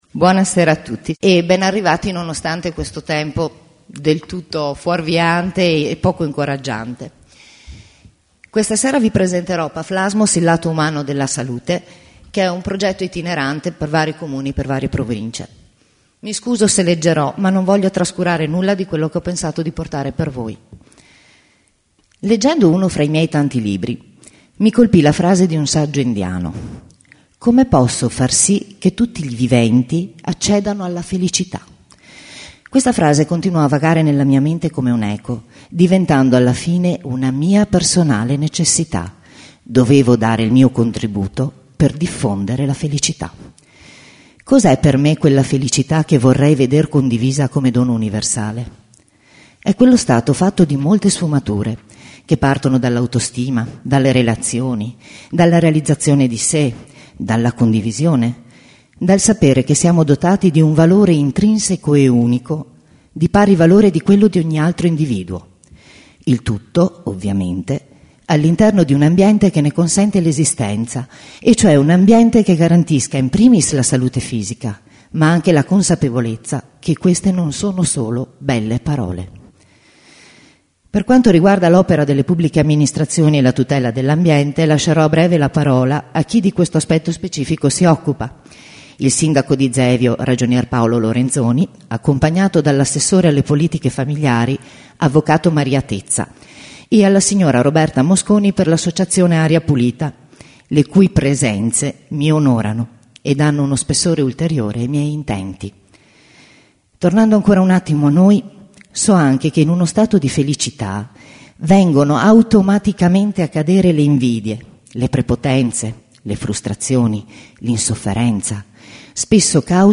Lunedì 25 ottobre 2010 ore 20.45 a Zevio -Vr-